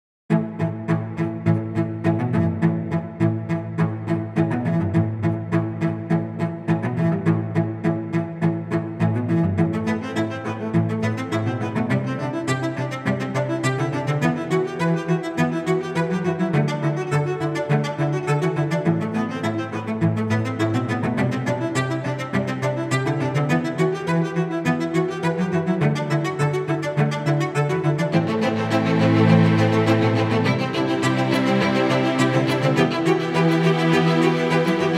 Жанр: Нью-эйдж